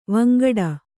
♪ vangaḍa